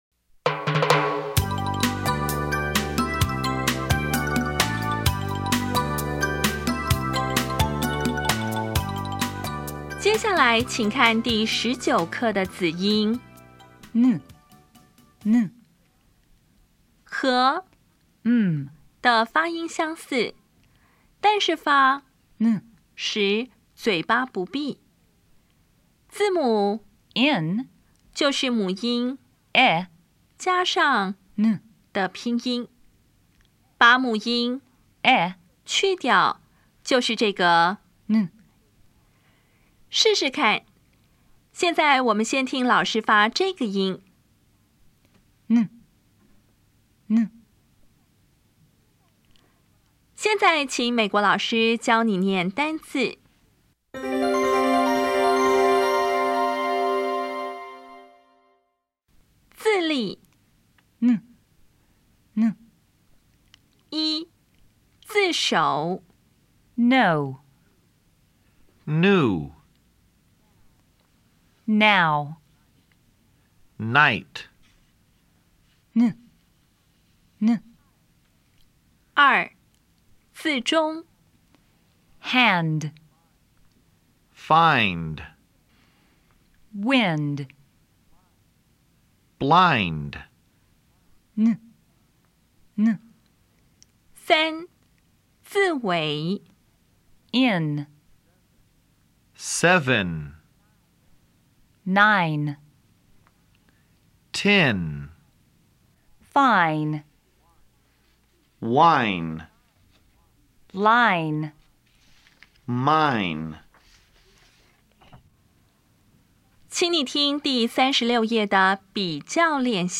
当前位置：Home 英语教材 KK 音标发音 子音部分-2: 有声子音 [n]
音标讲解第十九课
比较[m][n]       [m](有声/鼻音) [n](有声/鼻音)